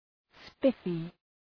Προφορά
{‘spıfı}